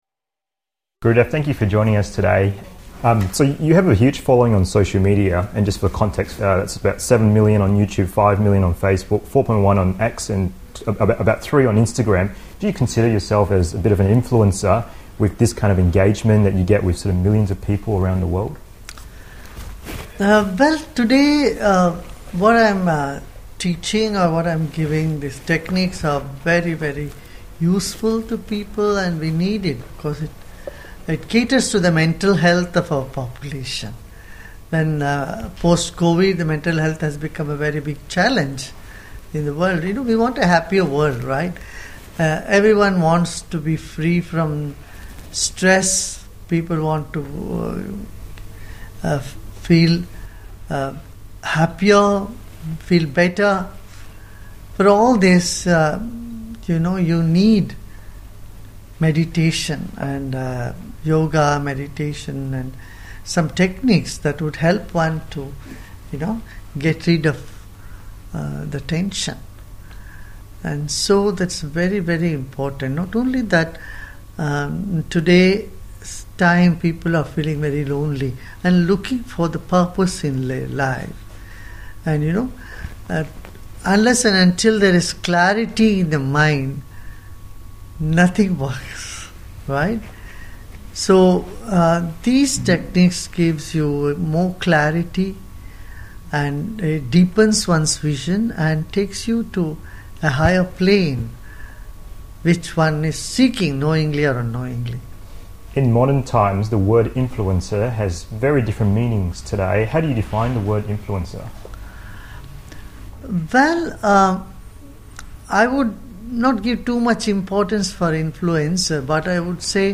INTERVIEW Spiritual leader Gurudev Sri Sri Ravi Shankar on happiness and the conscious life